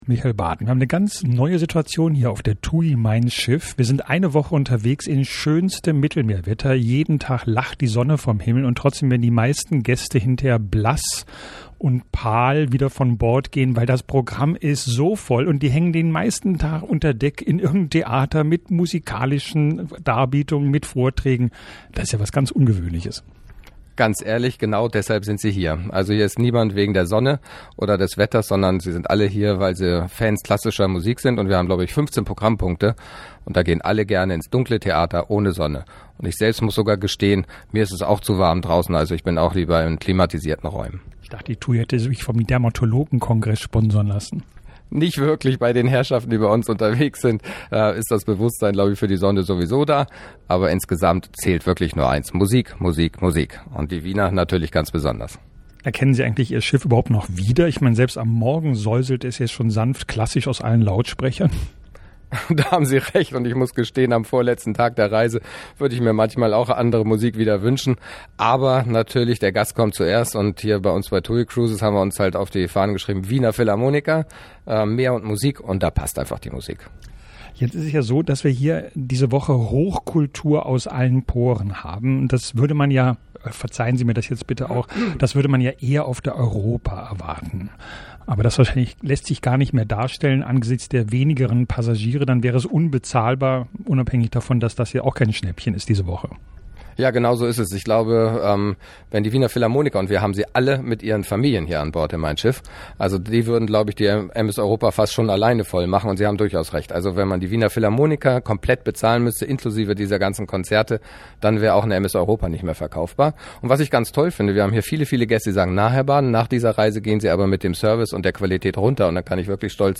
Touristik Talk